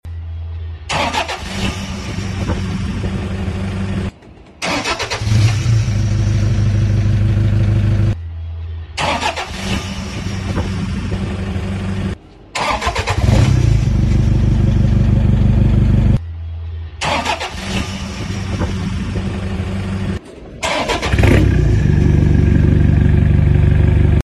Same car, same system, different sound options. System: Redback 3” Extreme Duty Car: 2010 Toyota Hilux Turbo Backed Turn It Up And Grab Sound Effects Free Download.